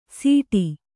♪ sīṭi